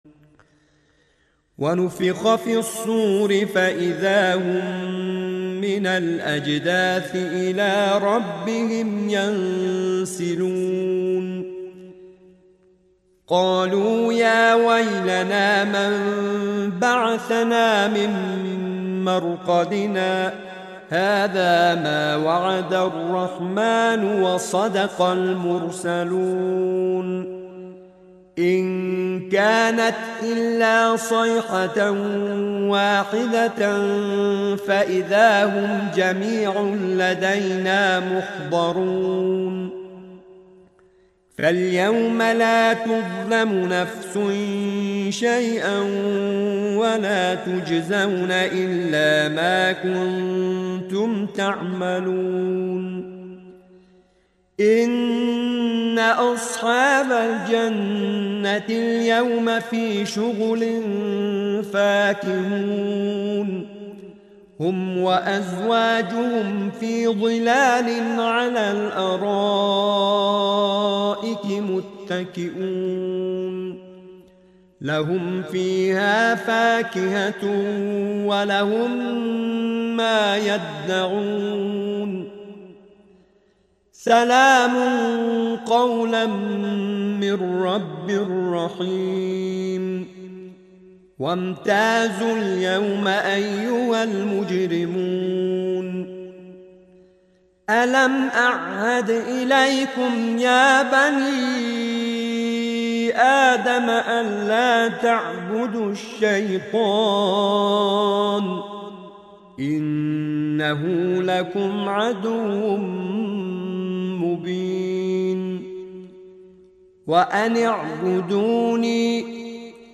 سوره مبارکه یس آیات 51 تا 70/ نام دستگاه موسیقی: سه‌گاه